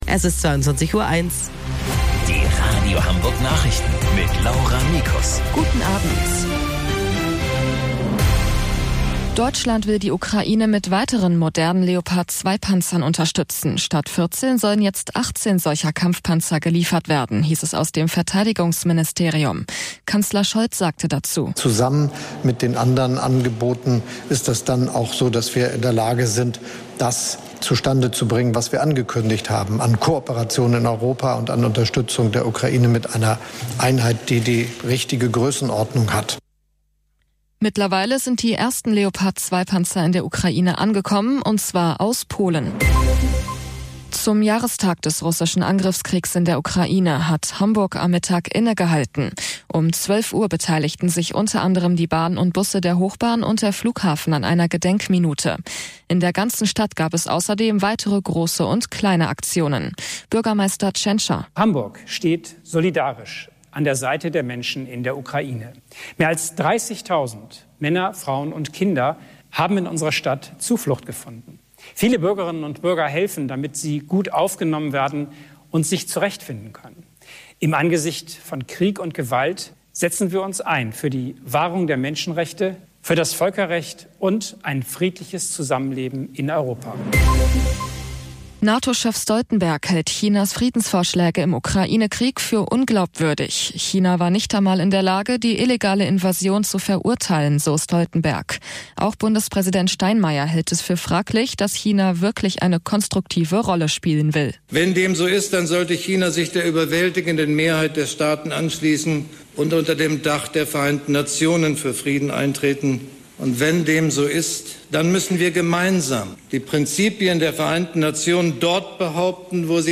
Radio Hamburg Nachrichten vom 03.10.2022 um 00 Uhr - 03.10.2022